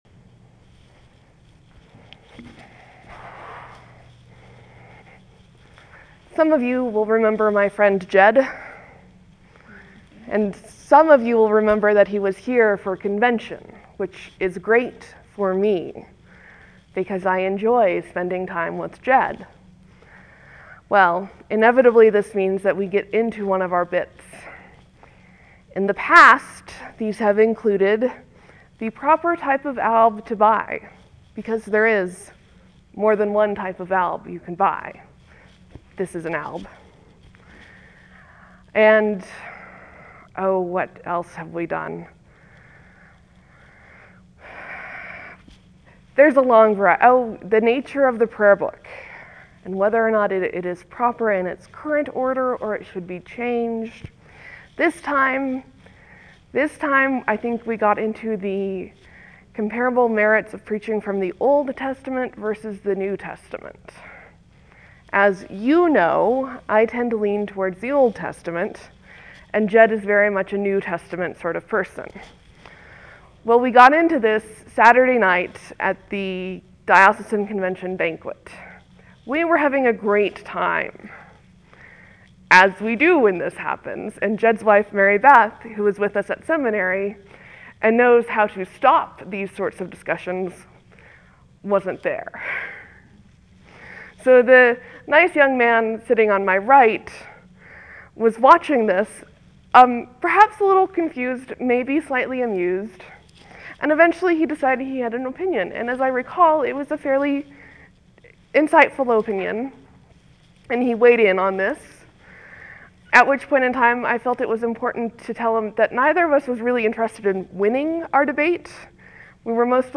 (There will be a few moments of silence before the sermon starts. Thank you for your patience.)